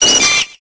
Cri de Chenipan dans Pokémon Épée et Bouclier.